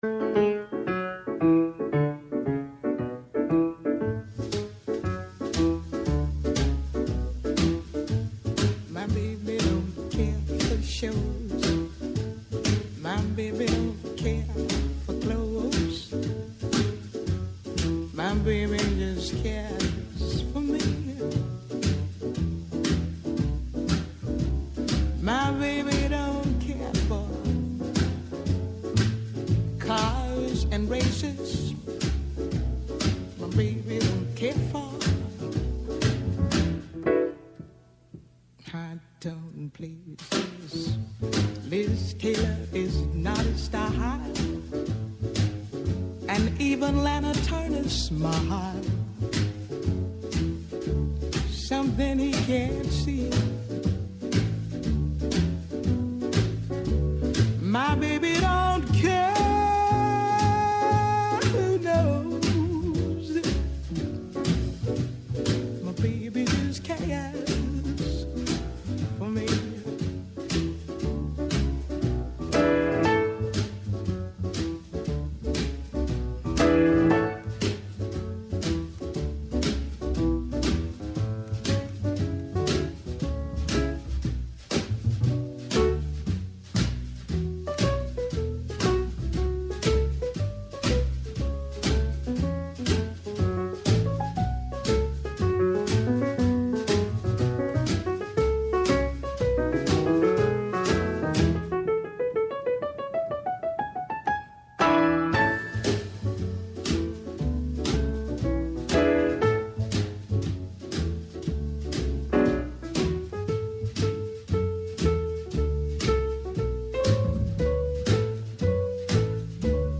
-Στο στούντιο της εκπομπής